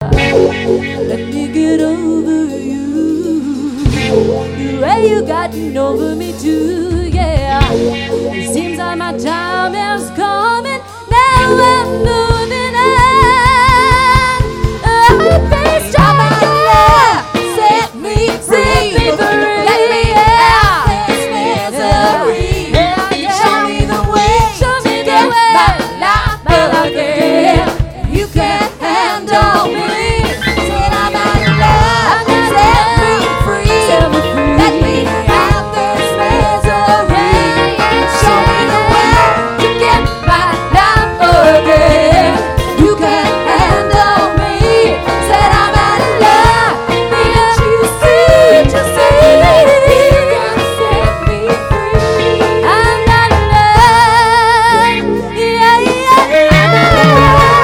PLAYING LIVE!!!!